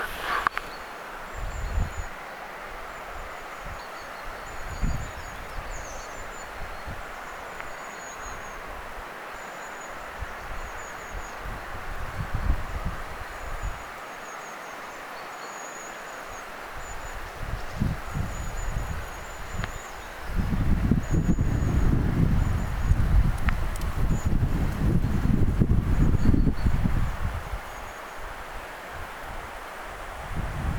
muuttava pyrstötiaisparvi, 1
muuttava_pyrstotiaisparvi.mp3